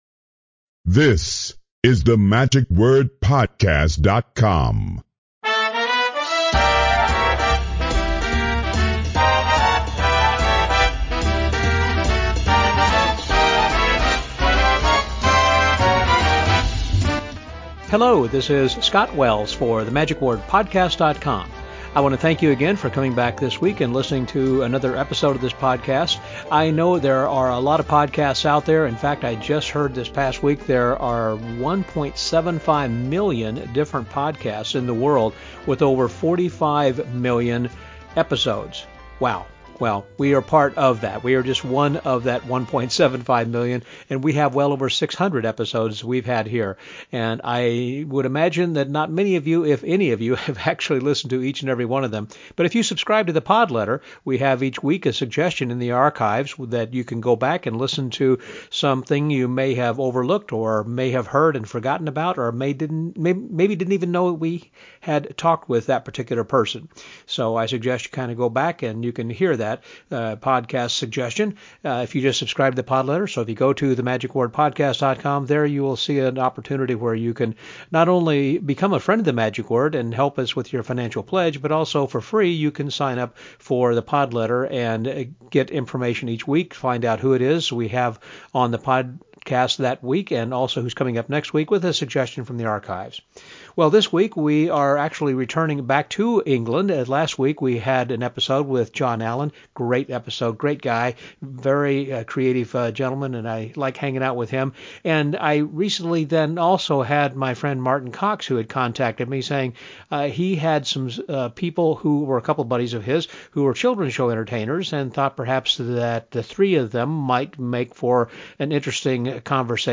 But there are some subtle differences in how and where magic is presented to children in the U.S. versus how it's done in the U.K. This week we chat with family and children show entertainers from the U.K. about these differences and some of thei